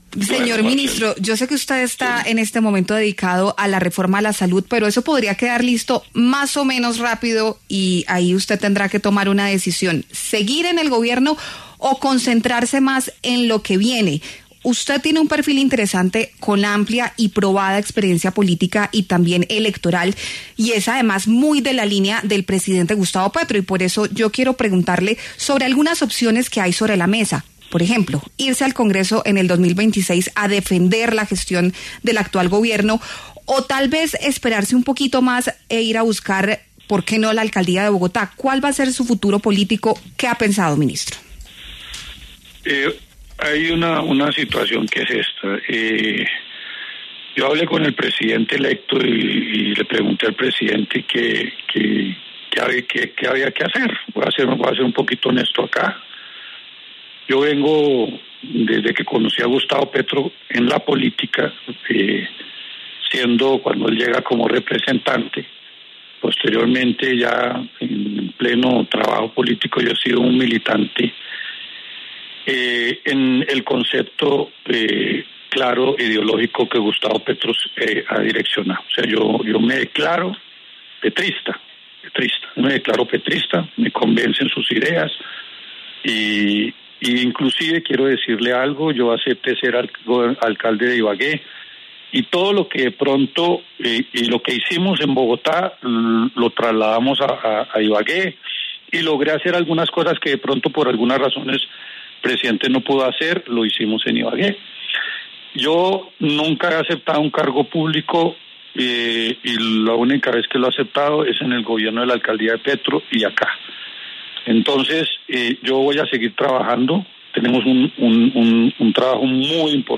Es por eso que, en conversación con La W, el ministro Jaramillo se refirió a su futuro político cuando salga del Gobierno, destacando que es “petrista” y seguirá luchando por ese proyecto.